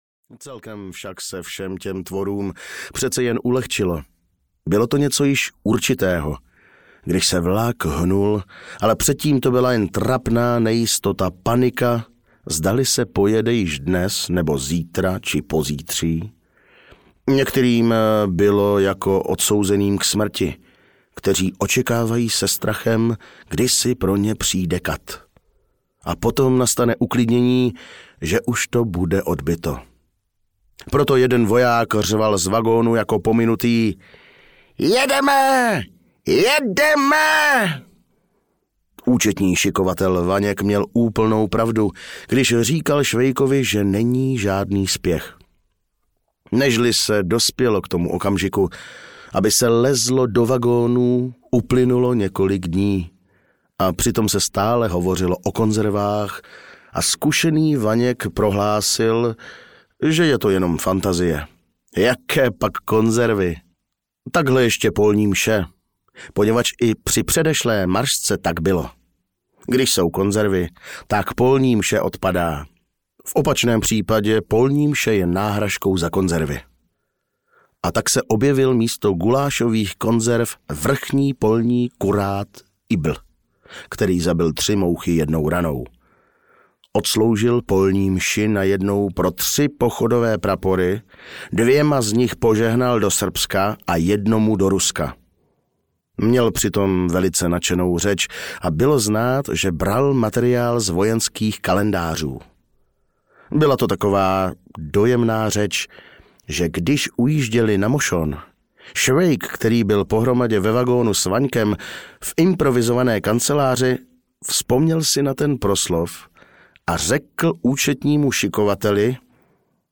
Osudy dobrého vojáka Švejka – Slavný výprask (3. díl) audiokniha
Ukázka z knihy